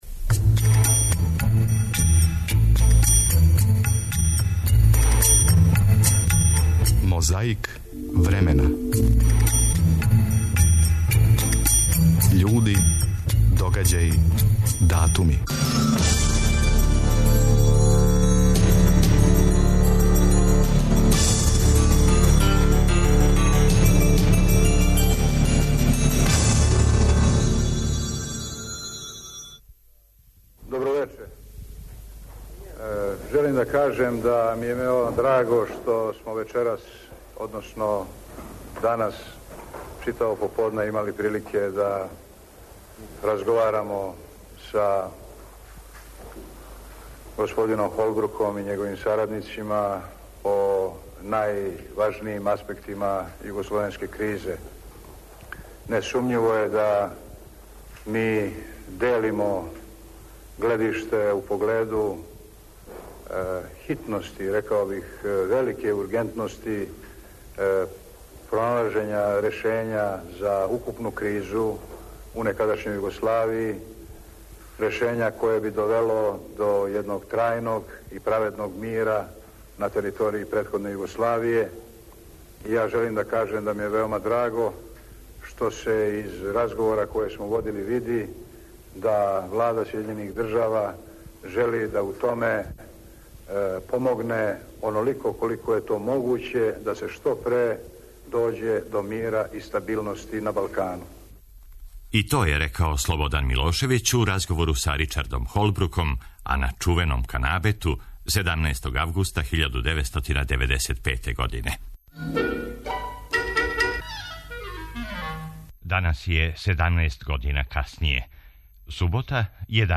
Једног августа смо поново и ми били мали део историје, јер на таласима Првог програма Радио Београда 14. августа 1988, у емисији "Недељом у 10" уживо се догодио народ у Старој Пазови, а чућемо и делове из репортаже Радио Београда у Драичићима код Штрпца из 2000, мало пре октобарских промена.